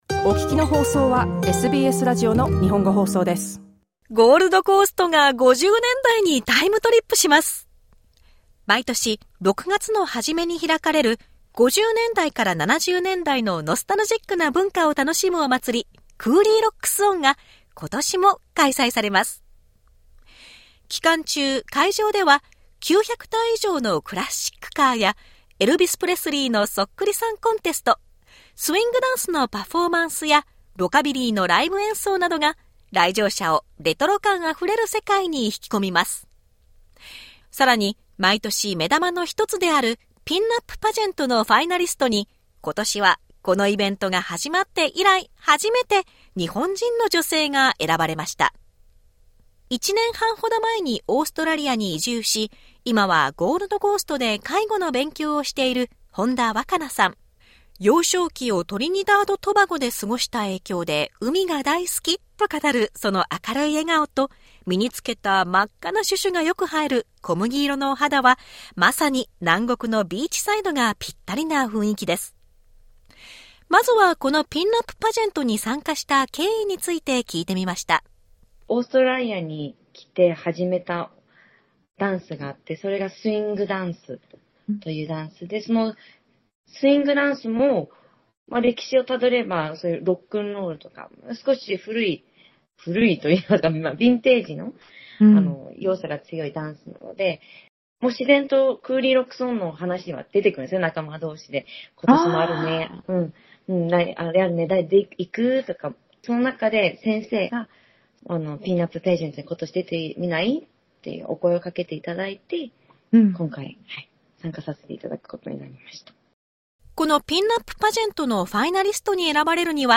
ピンアップ・パジェントに参加したきっかけ、最終戦に向けた意気込みなどについて聞いてみました。